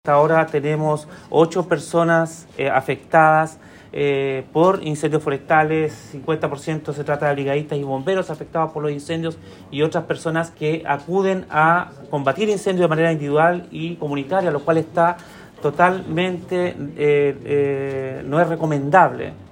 Con respecto al combate de los incendios forestales y las altas temperaturas, ocho personas han resultado con problemas de salud de diversa consideración, tal como lo indicó el seremi de Salud, Andrés Cuyul.